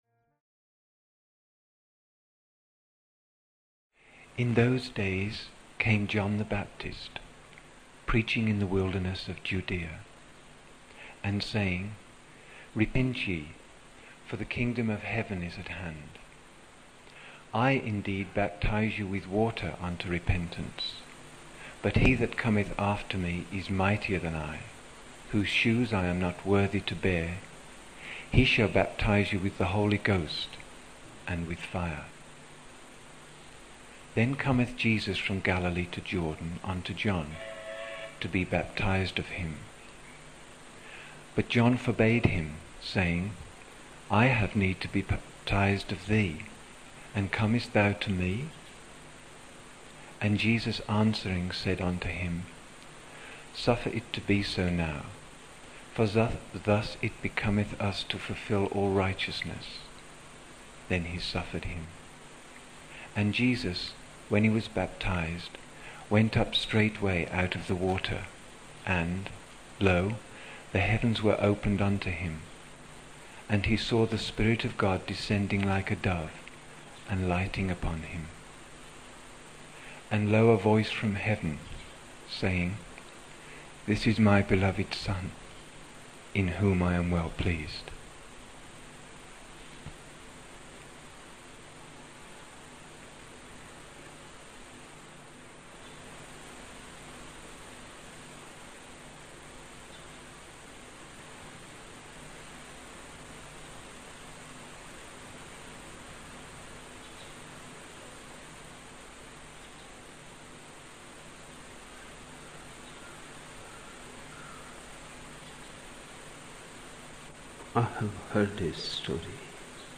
23 October 1975 morning in Buddha Hall, Poona, India